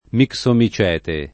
vai all'elenco alfabetico delle voci ingrandisci il carattere 100% rimpicciolisci il carattere stampa invia tramite posta elettronica codividi su Facebook mixomicete [ mik S omi ©$ te ] (meno com. missomicete ) s. m. (bot.)